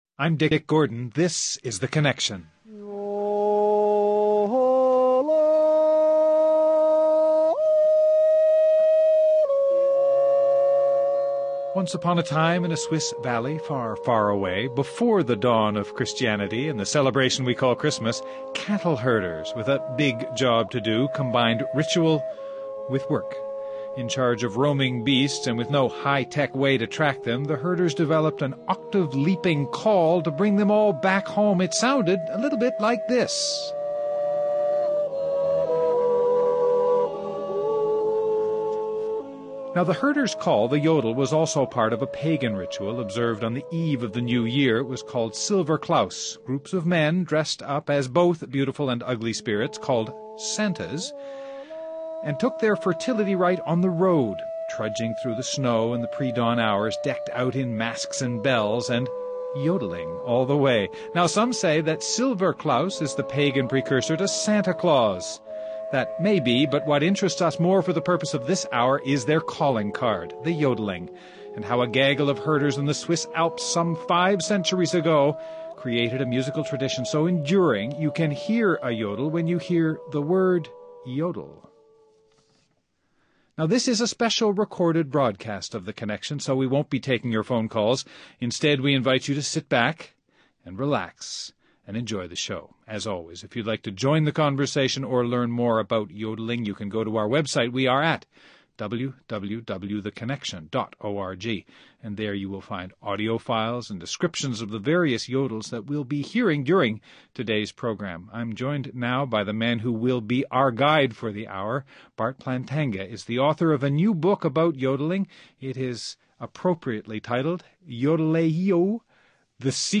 What likely began as a communication means for cattle herders in the Swiss Alps has enjoyed some artful adaptations, from cowboy ballads and the blues, to rap and rock n’ roll. And the story of the yodel’s evolution from occupational past-time to cultural icon, and from the agrarian Alps to Appalachia and beyond, is as much about migration as it is about music. The hills, and our studio, are alive with the sounds of music.